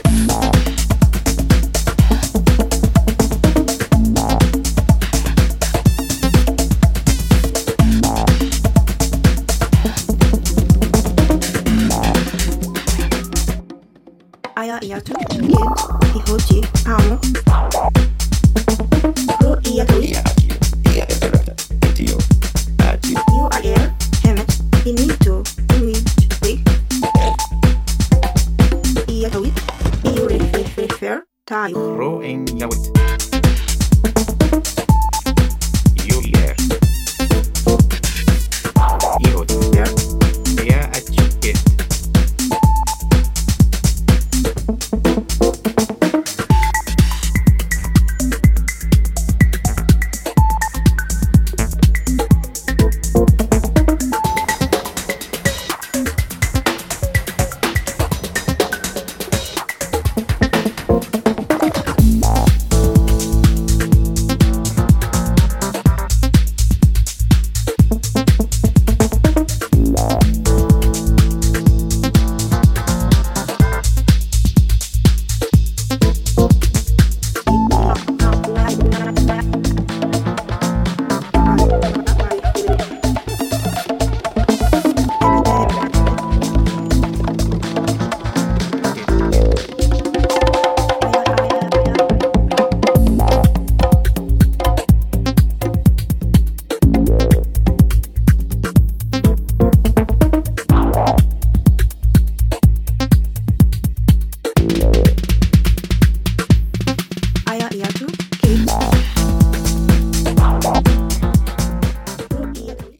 絶妙なテンションをキープするミニマル・アシッド・ハウス